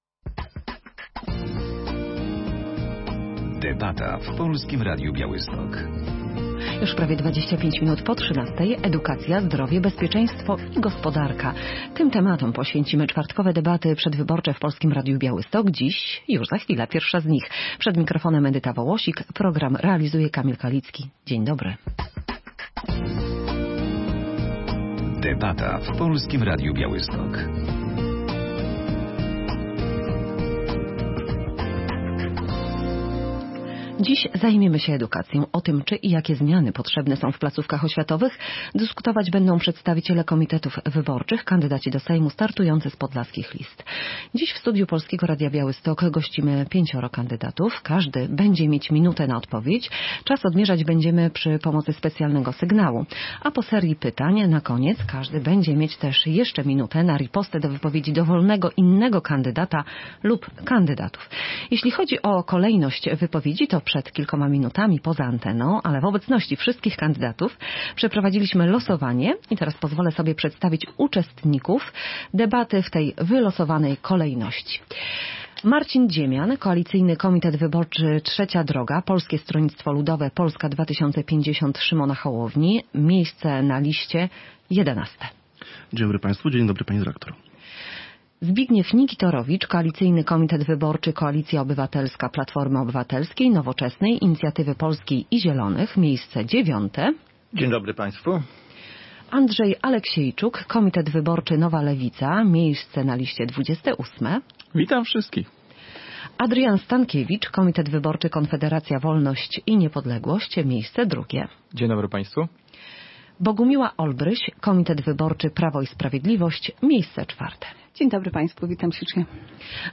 Wybory parlamentarne 2023. Debata w Polskim Radiu Białystok o edukacji [zdjęcia]